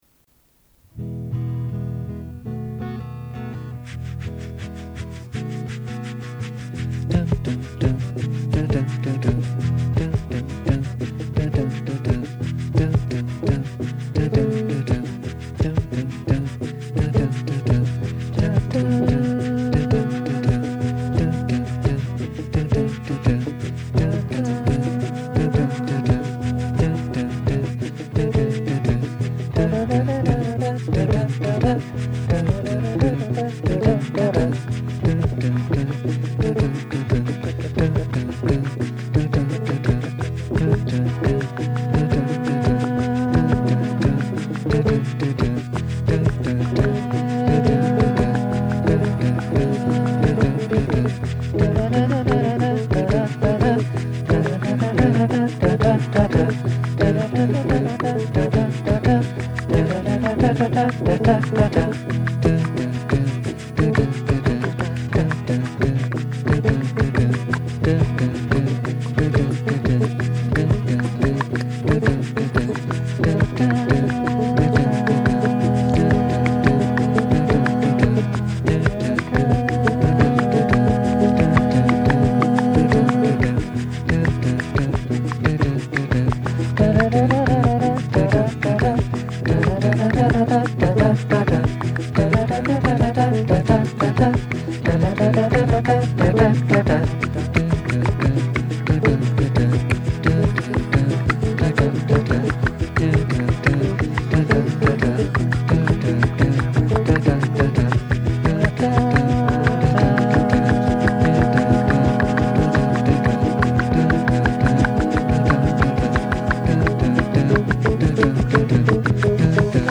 first home recording session   (performer)